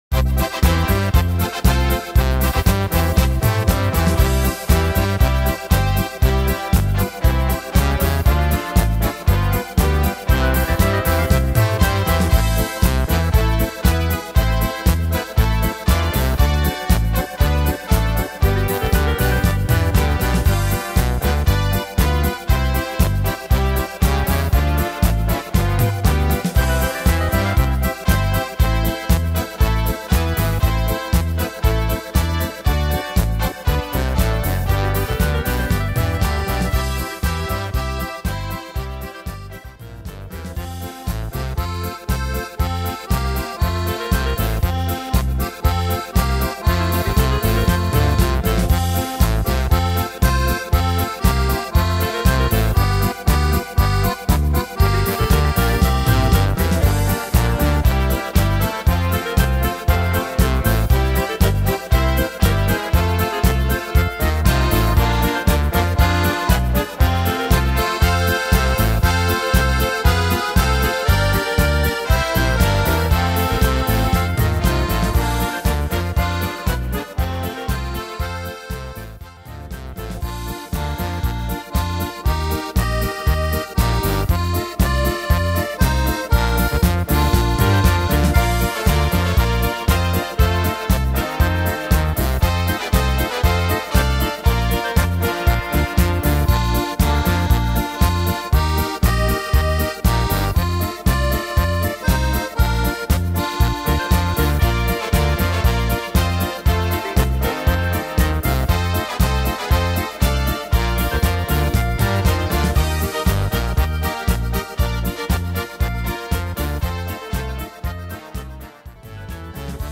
Tempo: 118 / Tonart: C-Dur